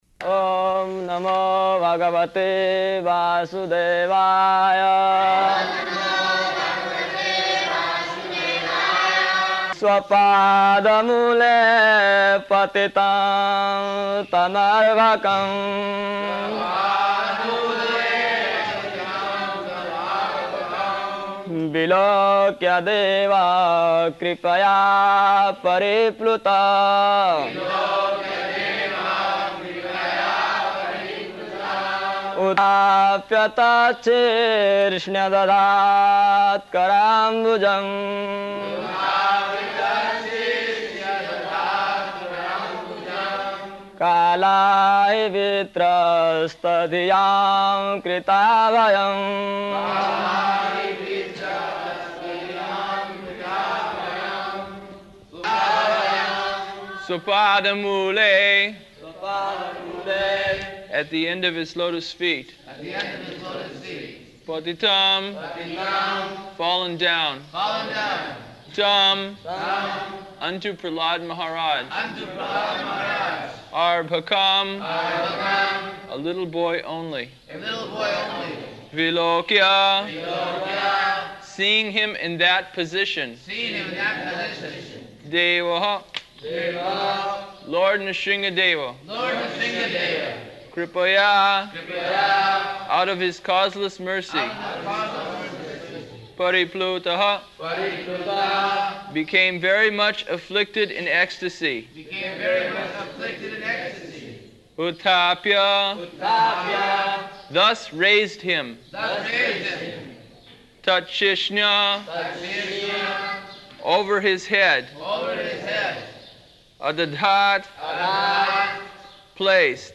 -- Type: Srimad-Bhagavatam Dated: February 12th 1976 Location: Māyāpur Audio file
[devotees repeat] [chants verse, with devotee repeating]